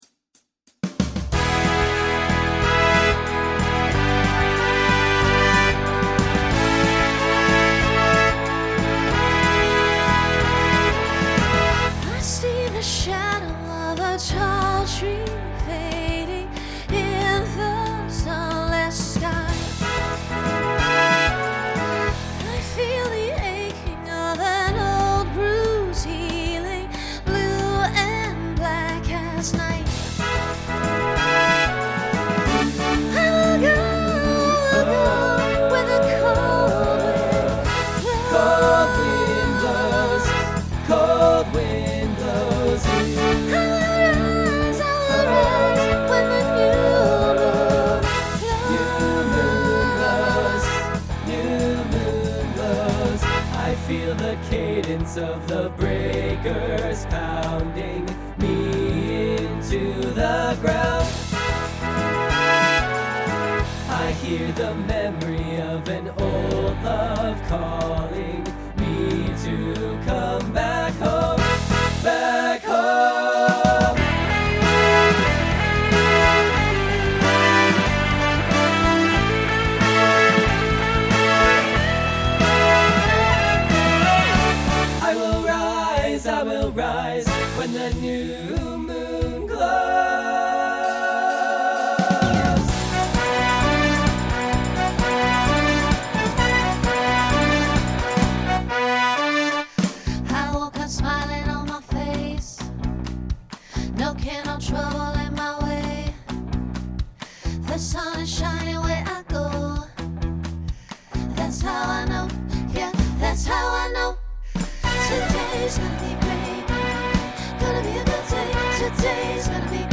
Genre Pop/Dance , Rock Instrumental combo
Voicing Mixed